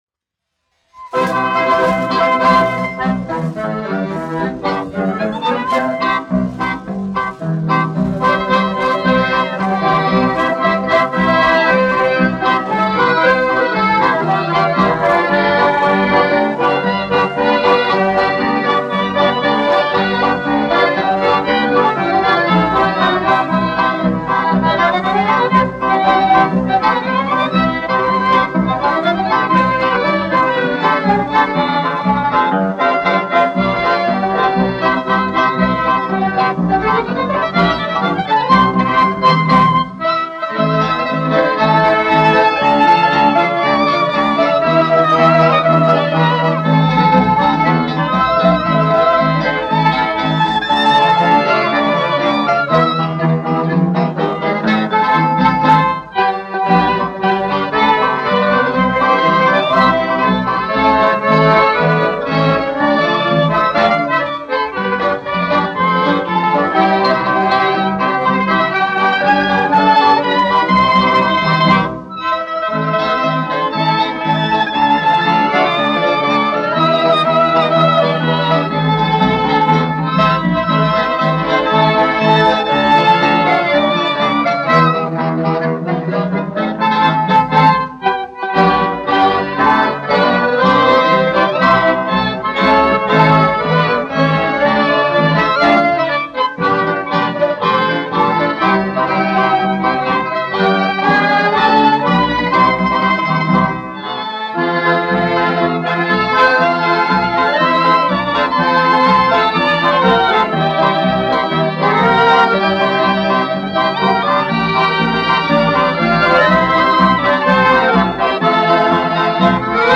1 skpl. : analogs, 78 apgr/min, mono ; 25 cm
Marši
Skaņuplate